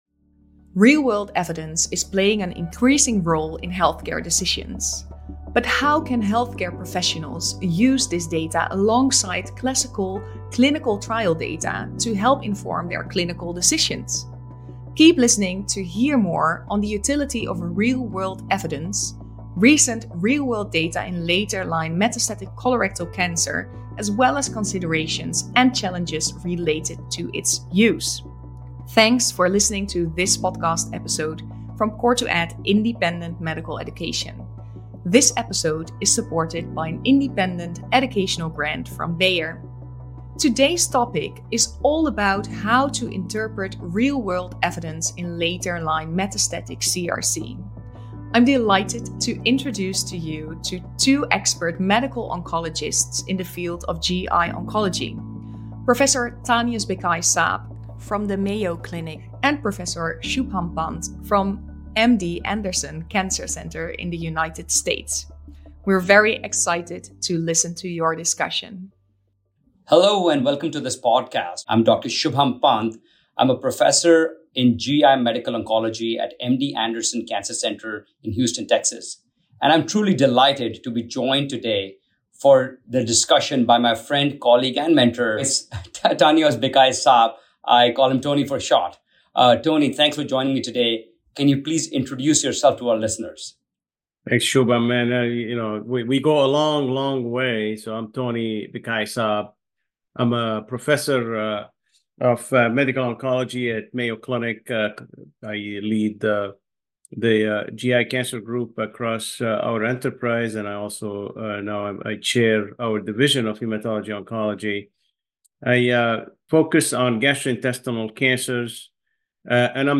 If you are able, we encourage you to listen to the audio, which includes emotion and emphasis that is not so easily understood from the words on the page.